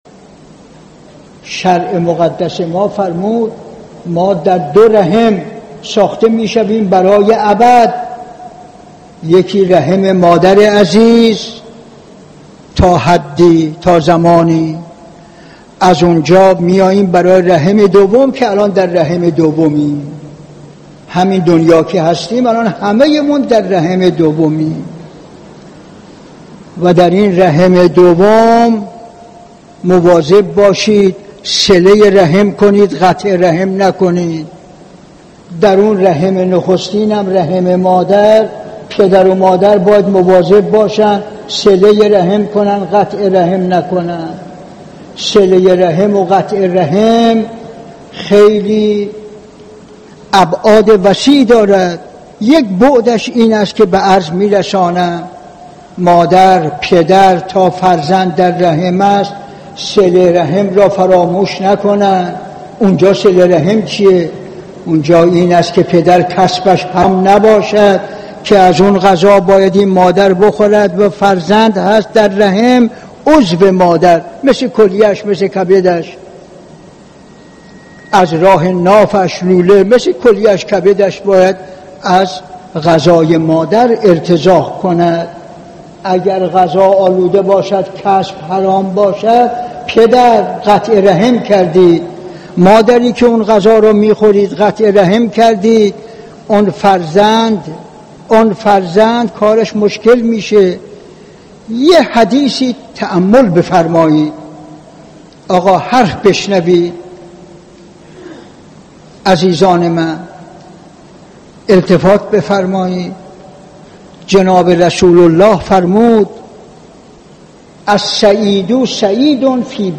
درس اخلاق | چرا اسلام بر صله‌رحم تأکیدی ویژه دارد؟
به گزارش خبرگزاری حوزه، مرحوم آیت الله حسن زاده آملی، عارف و فیلسوف جهان تشیع، در یکی از دروس اخلاق خود به موضوع «فلسفه صله رحم» پرداختند که تقدیم شما فرهیختگان می شود.